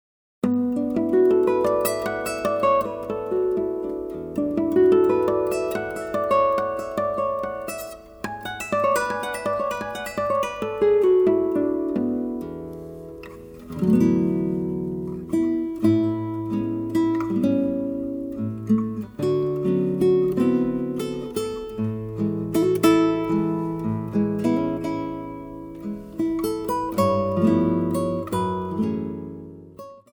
seven-string acoustic guitar